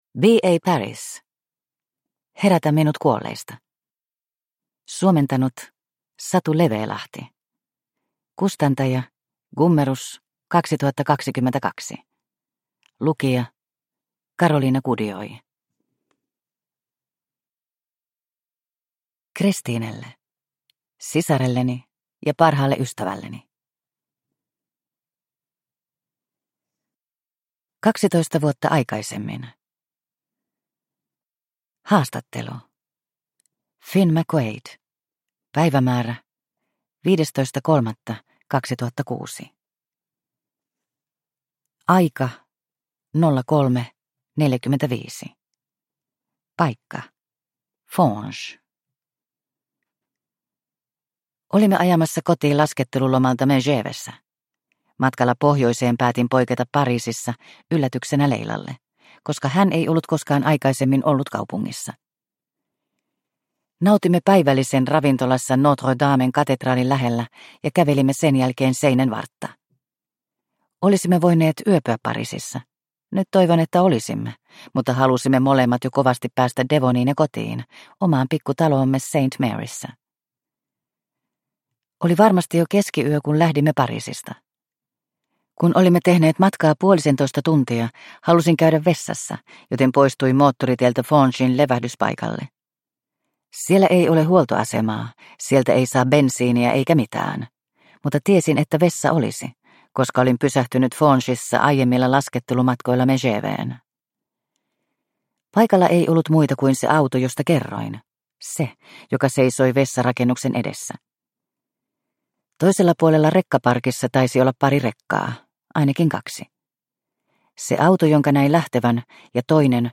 Herätä minut kuolleista – Ljudbok – Laddas ner